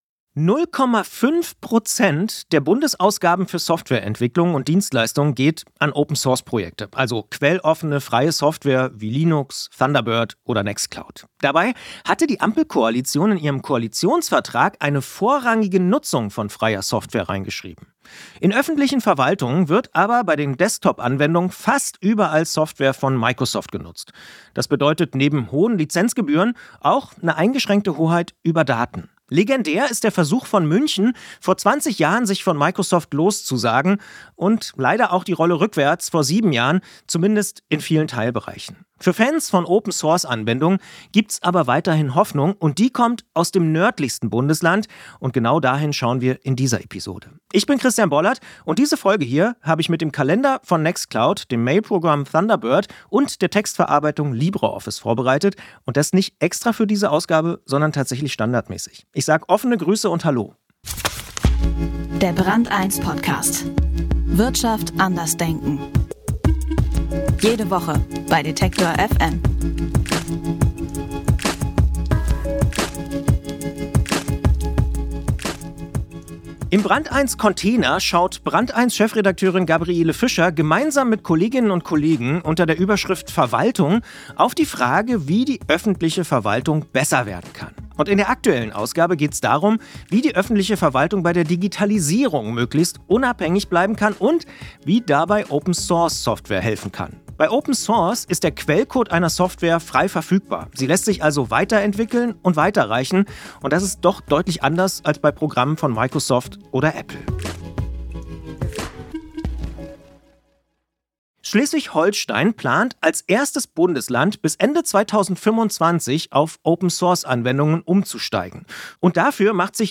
Wie das gelingen kann, erklärt Dirk Schrödter im Gespräch.